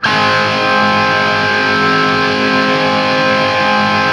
TRIAD G# L-L.wav